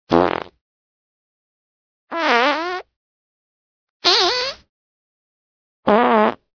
fart_1.ogg